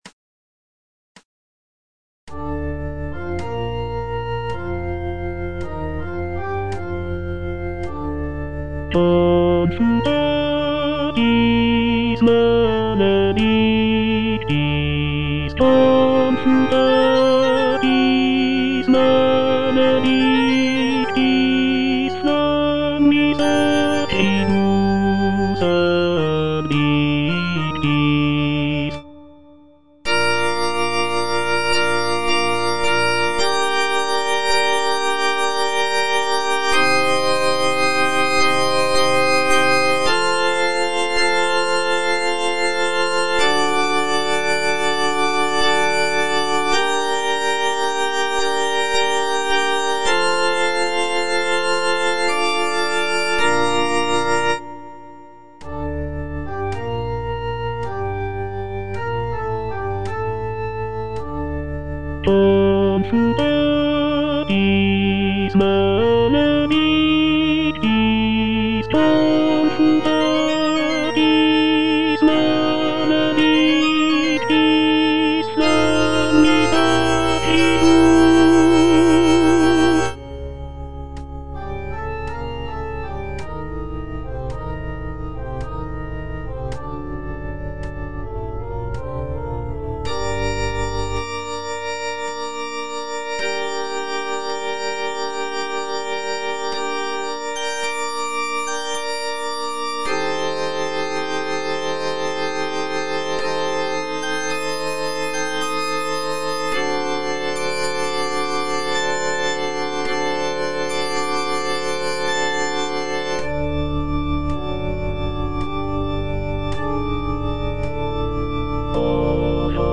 F. VON SUPPÈ - MISSA PRO DEFUNCTIS/REQUIEM Confutatis (tenor II) (Voice with metronome) Ads stop: auto-stop